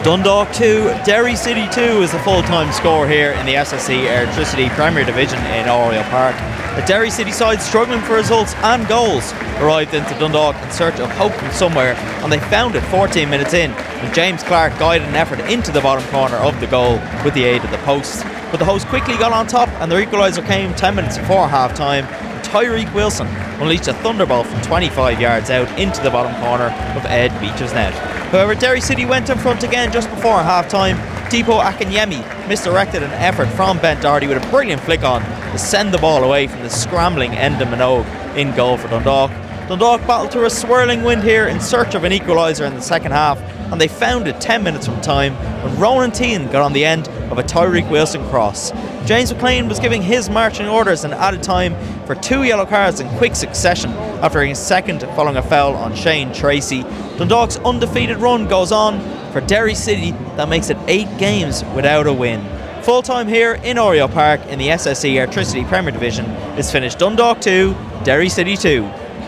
the full time report from Louth…